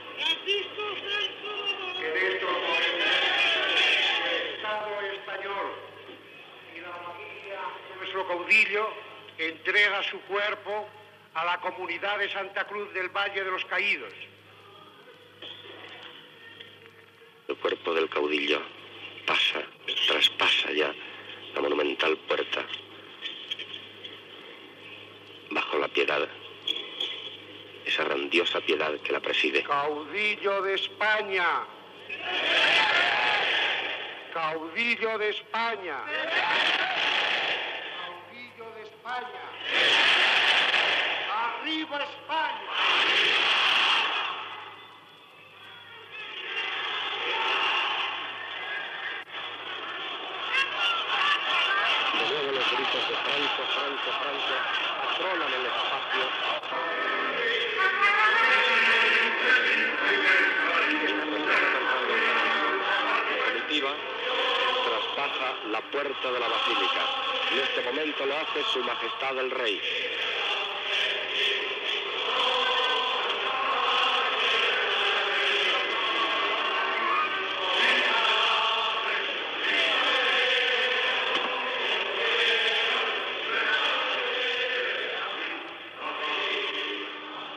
Arribada del taüt de Francisco Franco, en presència del rei Juan Carlos I, a la Basílica de la Santa Cruz del Valle de los Caídos, San Lorenzo de El Escorial, Comunidad de Madrid.
Informatiu